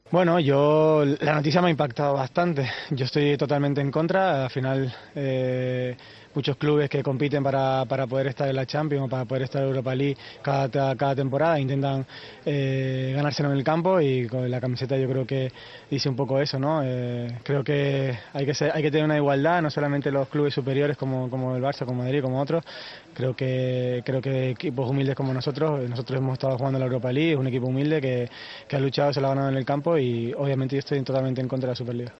AUDIO: El delantero azulón se ha mostrado contrario a la creación de esta nueva competición al término del partido ante el Barcelona.